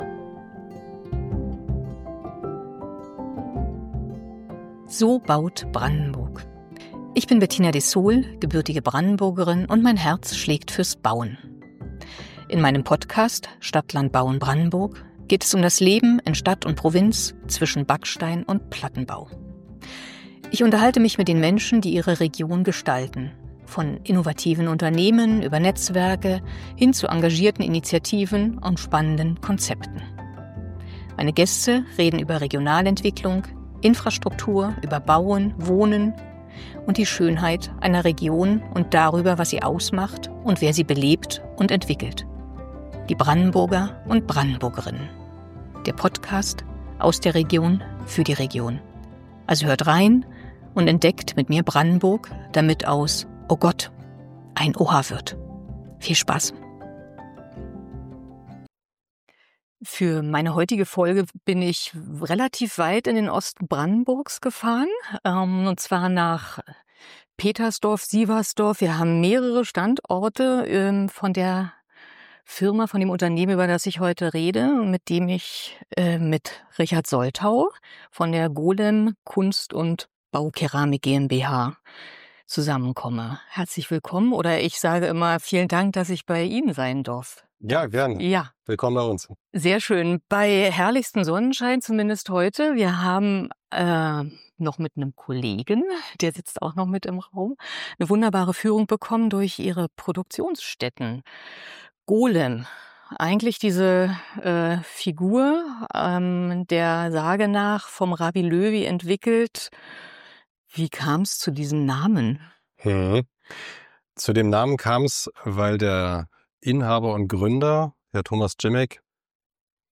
Konzept und Moderation